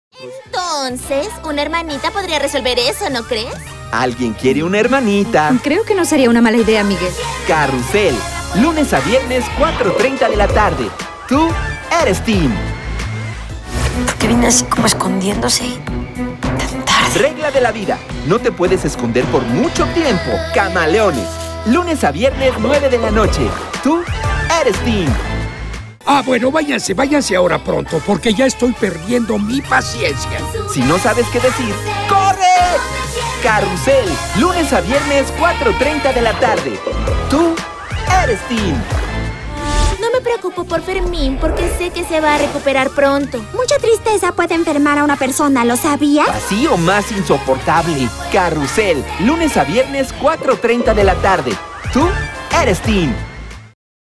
Male
Eloquent
Friendly
Energetic
Warm
Radio / TV Imaging
Words that describe my voice are Eloquent, Warm, Friendly.